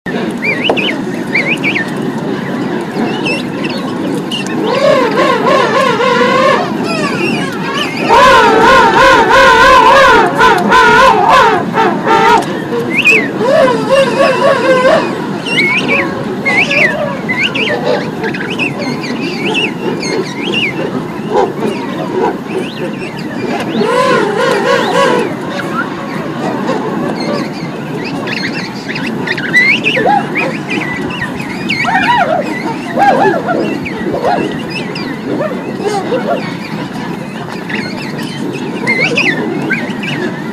La colonie de manchots royaux, le son